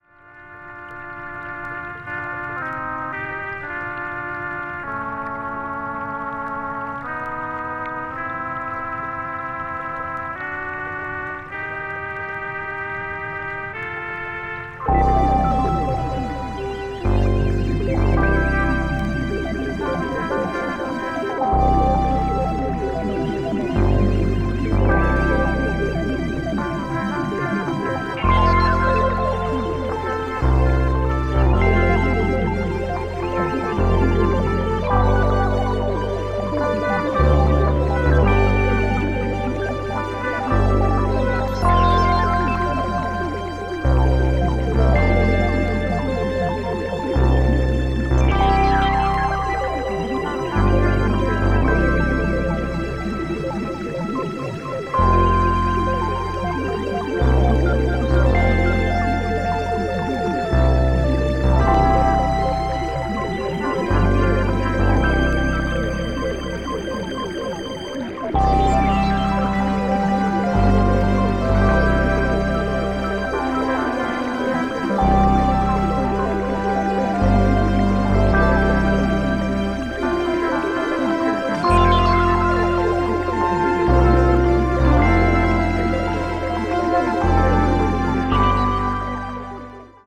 media : EX/EX(わずかにチリノイズが入る箇所あり)
electronic   progressive rock   psychedelic   synthesizer